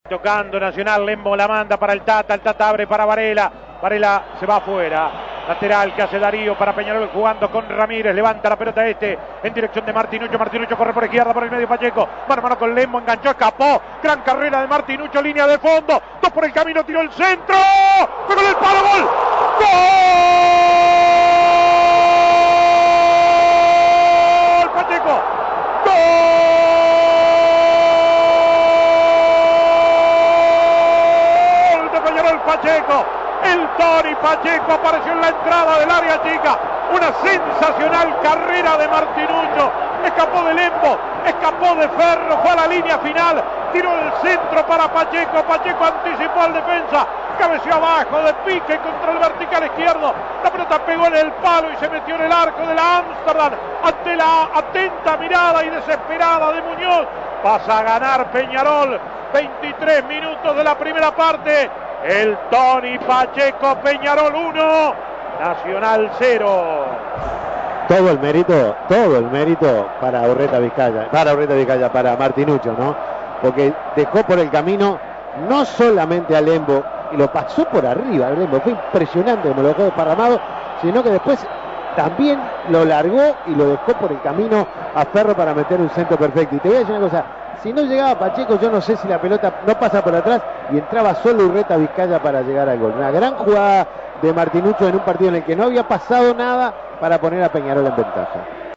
Goles y comentarios Escuche el gol de Peñarol en la primer final Imprimir A- A A+ Peñarol le ganó a Nacional 1-0 y se quedó con la primer final del campeonato uruguayo 0.